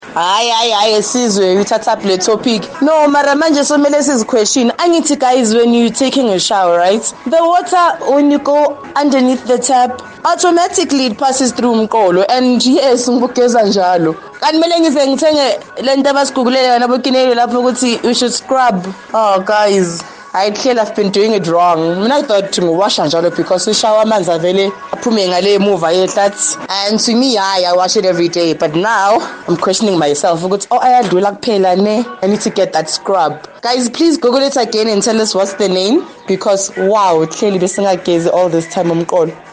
That is what Sizwe Dhlomo wanted to know on Kaya Drive this Monday.